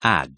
Hur uttalas ordet hat ? ['hɑːt]